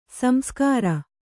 ♪ samskāra